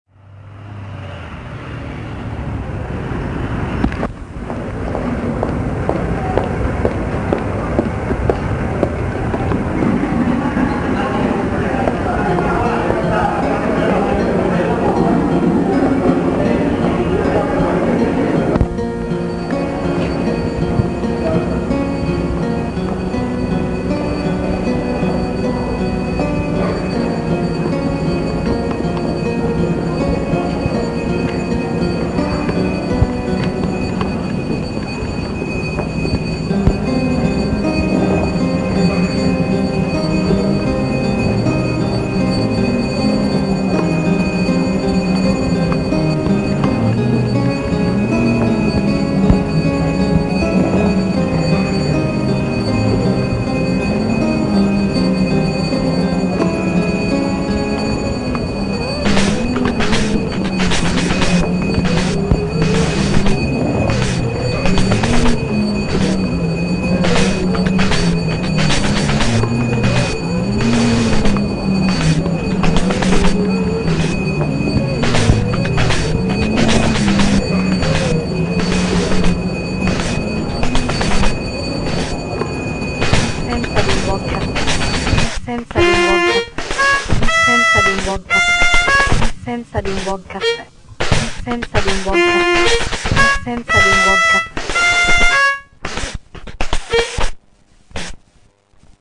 Si inizia a sporcare un po' i suoni. Si inizia ad estrarre materiale inaudibile dalla cassetta incriminata.